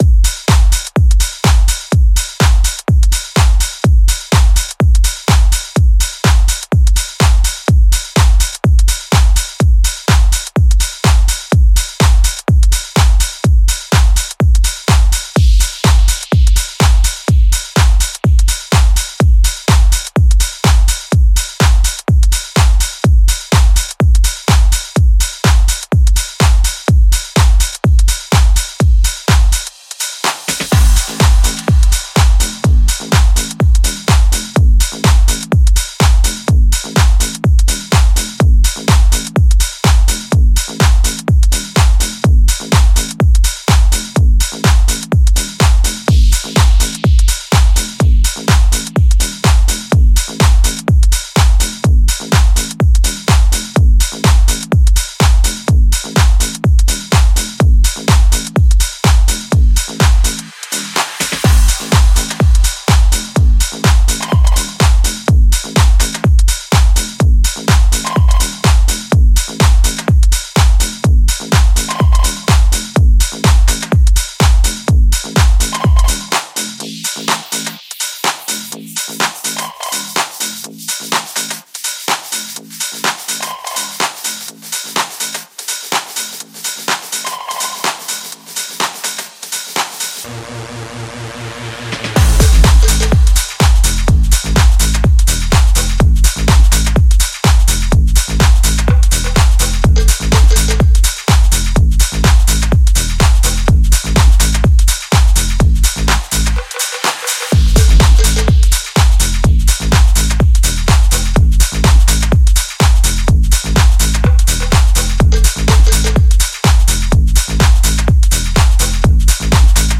house gem
powerful house music